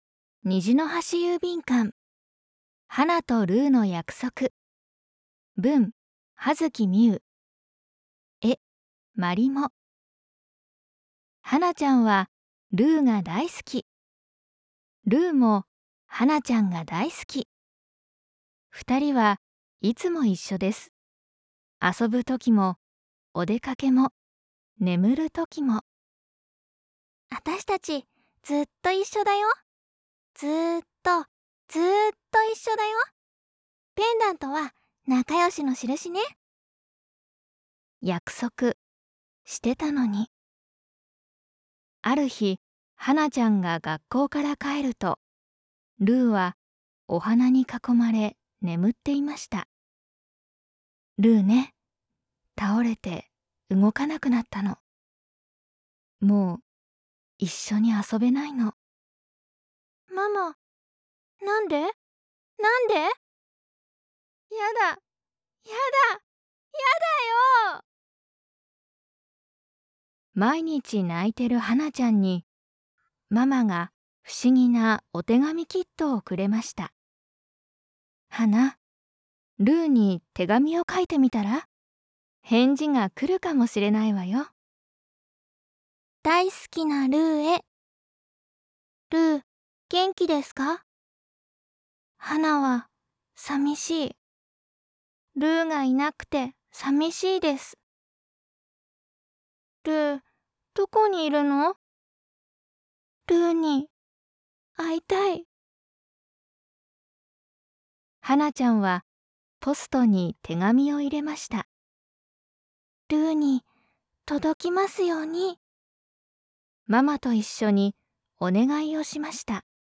ナレーター･声優･MC